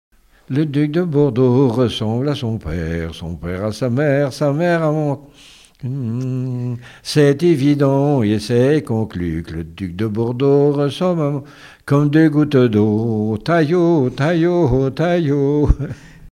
Mémoires et Patrimoines vivants - RaddO est une base de données d'archives iconographiques et sonores.
Témoignages et chansons
Pièce musicale inédite